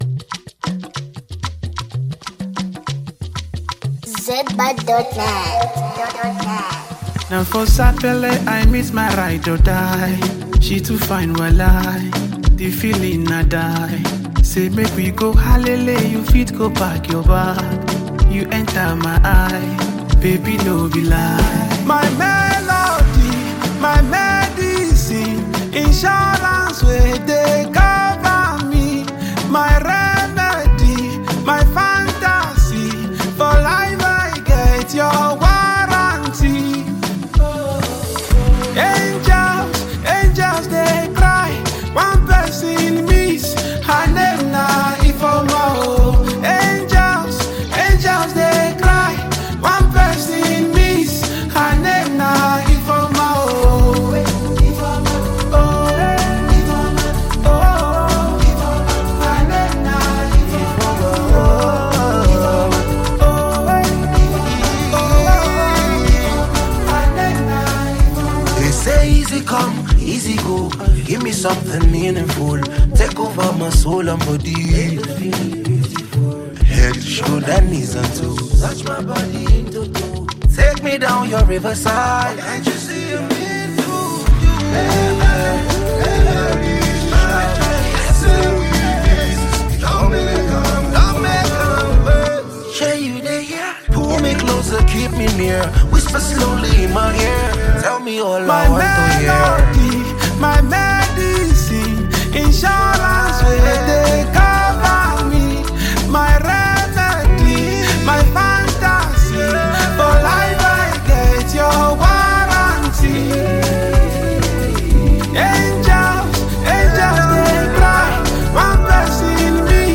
b> is known for his smooth vocals